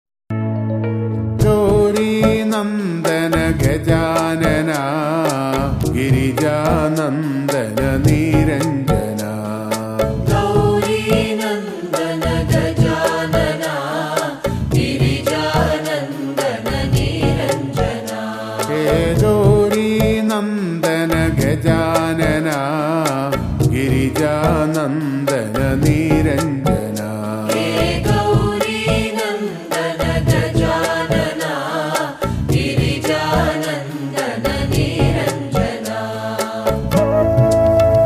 bhajans